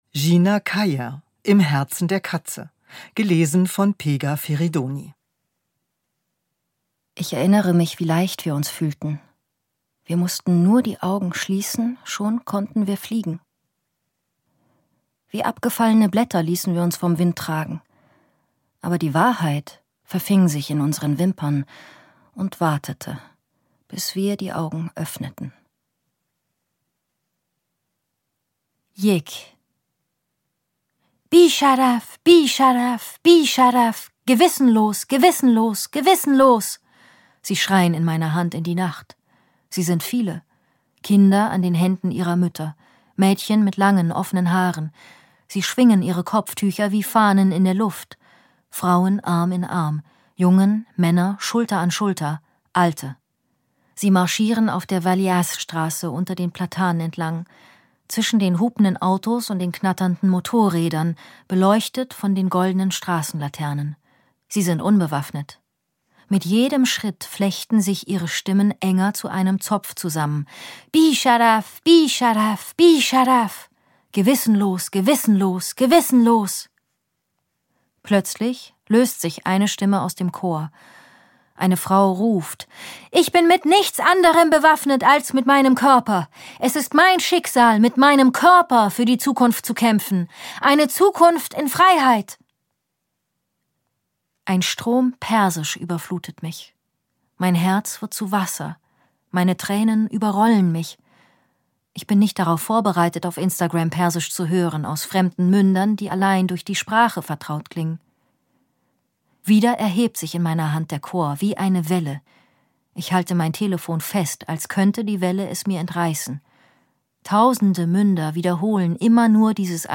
Pegah Ferydoni (Sprecher)
Ungekürzte Lesung mit Pegah Ferydoni